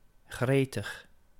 Ääntäminen
US : IPA : [iː.ɡə(ɹ)]